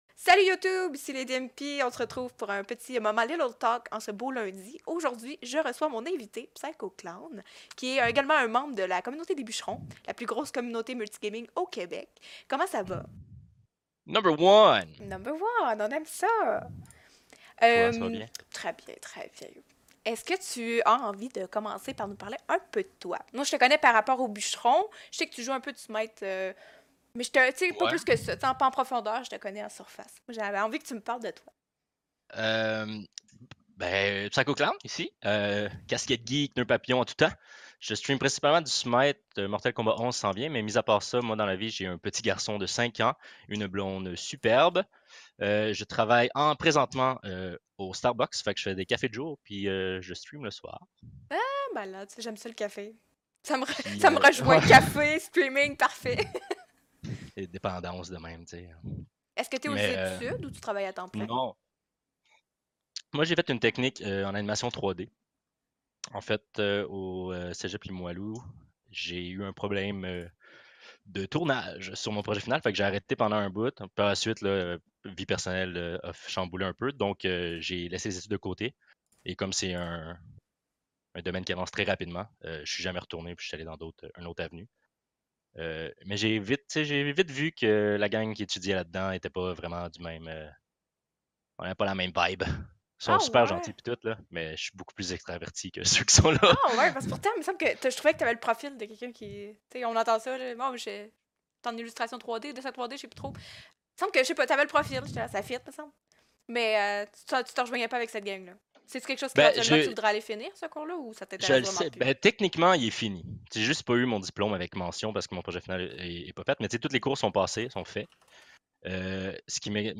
Invité et sujet différents à chaque fois, on papote, on s'instruit et surtout on a du plaisir!!Les premiers épisodes sont des rendus de mes live Twitch. La qualité peut nettement être améliorée et j'en suis consciente!